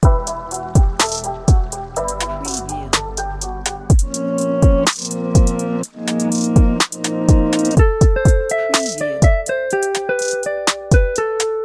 مجموعه سمپل های ترپ | بیش از 5 هزار سمپل برای سبک ترپ و رپ
مجموعه سمپل های ترپ | مجموعه 5 هزار تایی از سمپل های با کیفیت مخصوص سبک ترپ، هیپ هاپ و رپ | انواع لوپ های آماده سبک ترپ
demo-trap.mp3